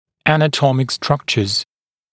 [ˌænə’tɔmɪk ‘strʌkʧəz][ˌэнэ’томик ‘стракчэз]анатомические структуры